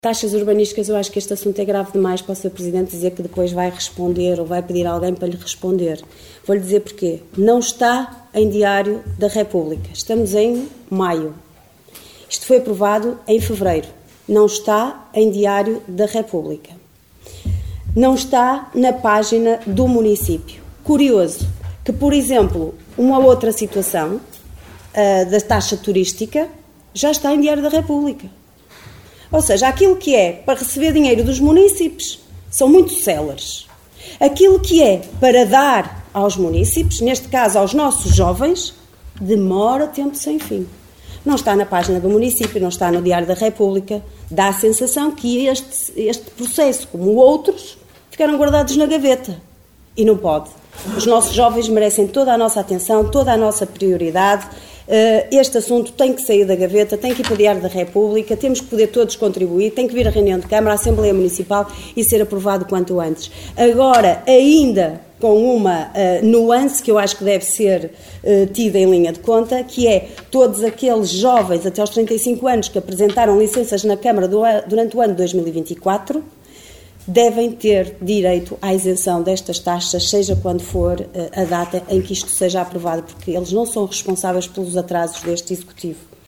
Liliana Silva a criticar a inércia da Câmara de Caminha relativamente à implementação da isenção das taxas urbanísticas para jovens até aos 35 anos.